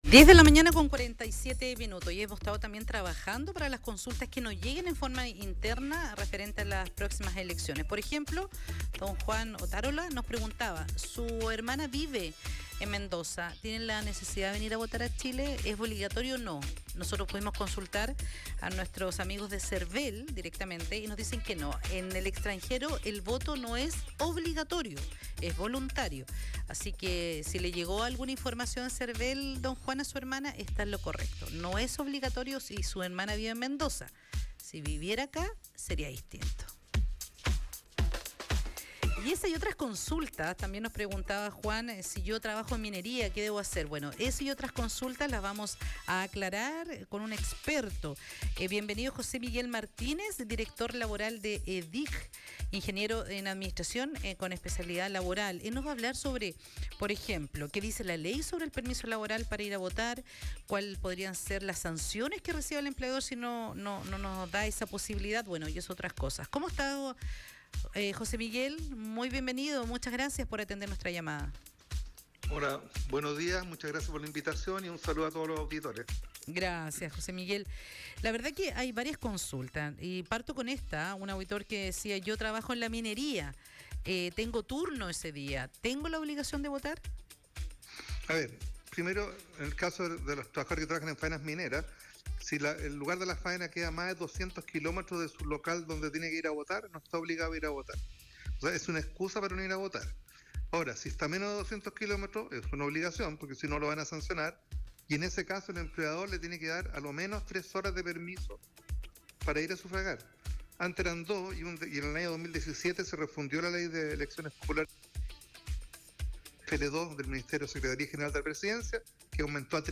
Entrevista Radio Carabineros sobre los Derechos Laborales durante elecciones